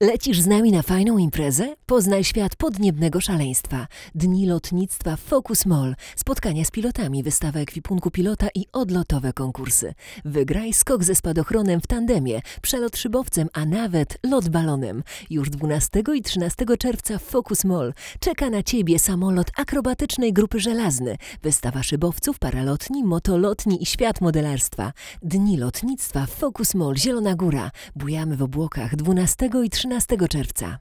Jest czysto, gładko i wyraźnie, a jednocześnie naturalnie.